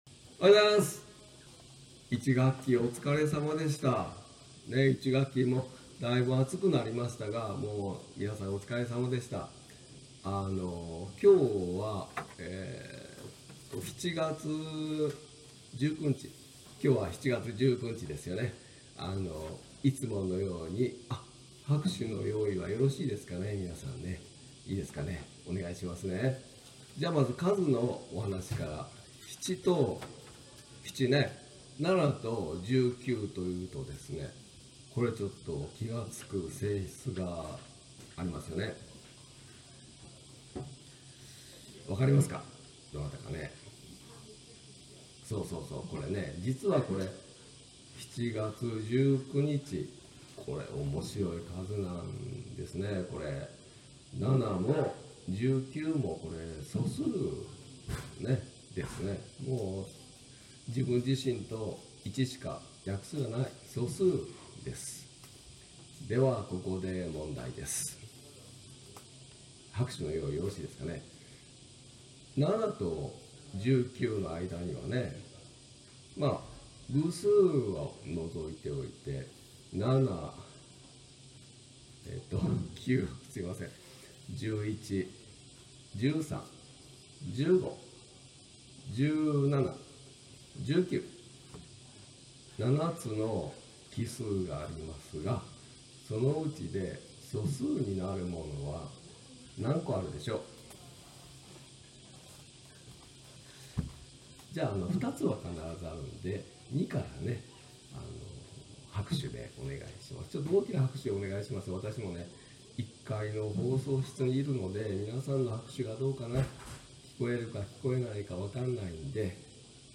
本日は附属中学校の終業式でした。今回もコロナウイルス感染予防対策として放送でのお話となりました。
７月19日(月)...１学期終業式の話【校長先生のお話】（音声のみ12MB程度）